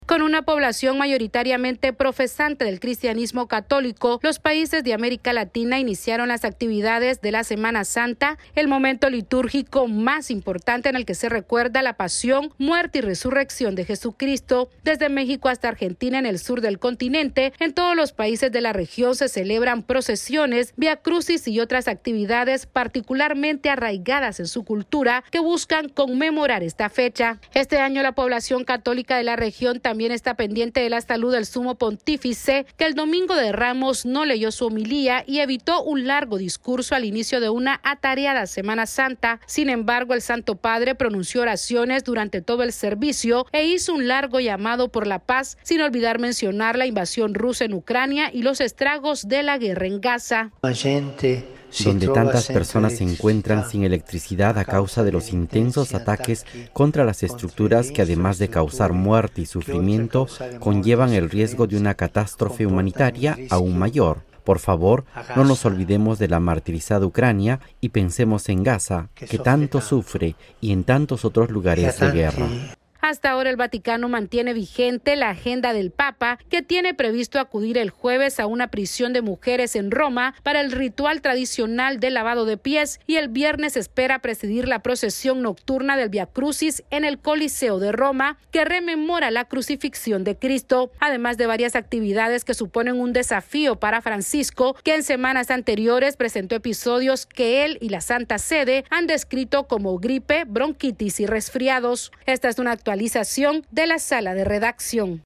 América Latina inició la conmemoración de la Semana Santa, una de las festividades religiosas más importante para los creyentes católicos que se mantienen atentos a la salud del Papa Francisco. Esta es una actualización de nuestra Sala de Redacción...